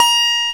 Index of /m8-backup/M8/Samples/Fairlight CMI/IIX/GUITARS
GUITAR2.WAV